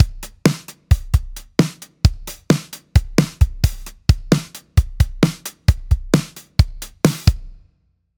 スネアの1/8ずらし
r1-synco-drum-snare8th.mp3